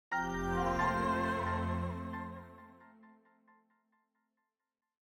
Longhorn 8 - Log On.wav